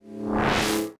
sci-fi_hologram_electric_buzz_01.wav